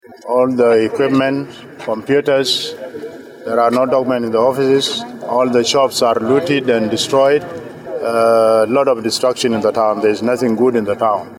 Embed share Jonglei Caretaker Governor John Koang on destruction in Bor by VOA Africa Embed share The code has been copied to your clipboard.